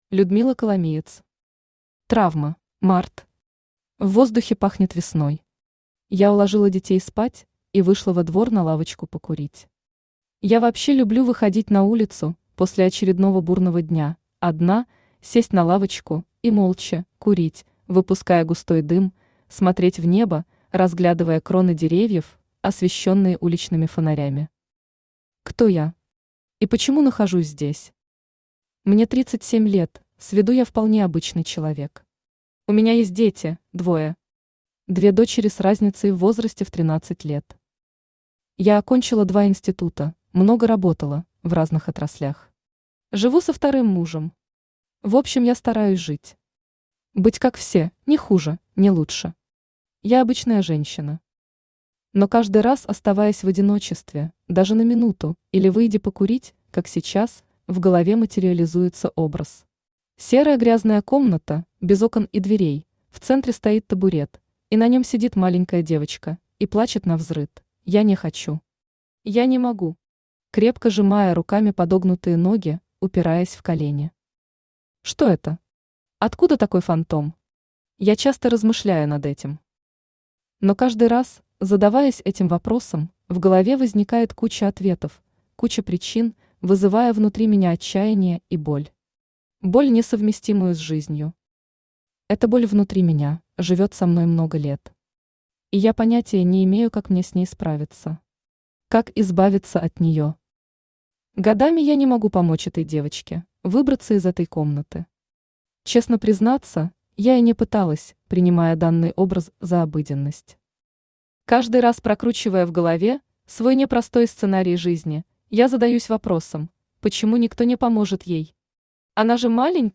Aудиокнига Травма Автор Людмила Коломиец Читает аудиокнигу Авточтец ЛитРес.